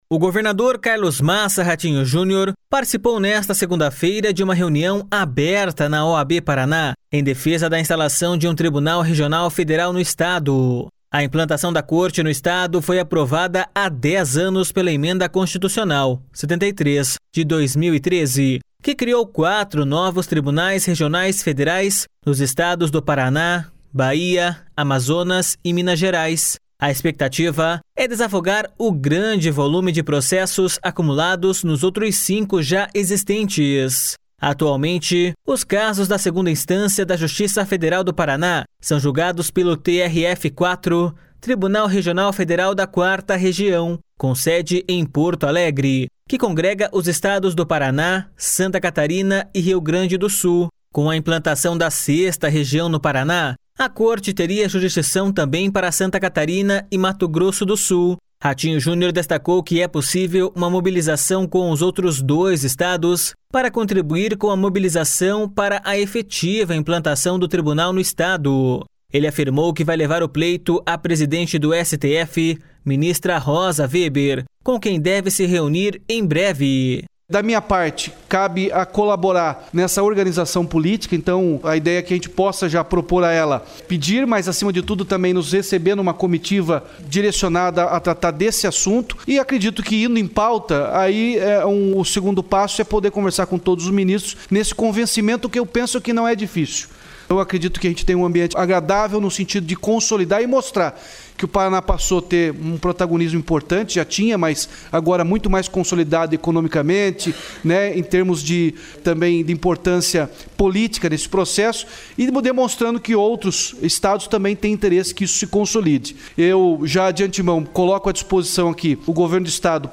O governador Carlos Massa Ratinho Junior participou nesta segunda-feira de uma reunião aberta na OAB Paraná em defesa da instalação de um Tribunal Regional Federal no Estado.